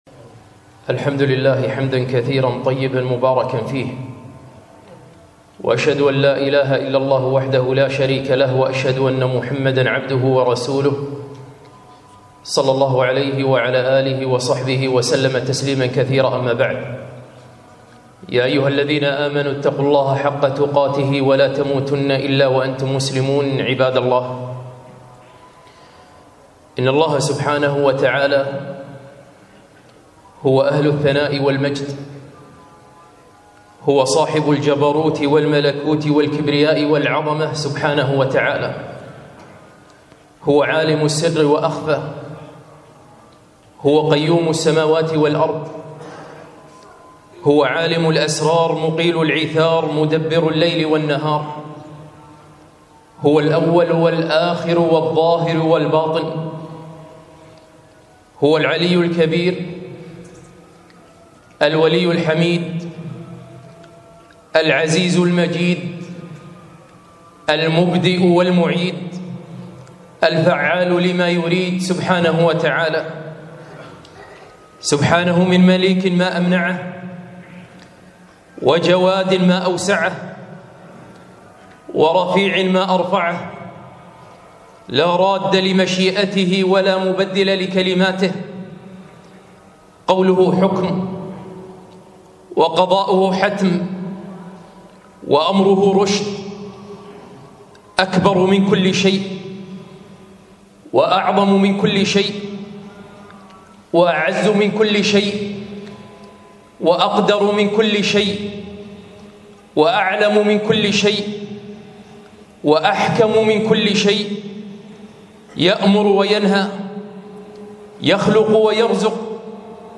خطبة - تعظيم الله عز وجل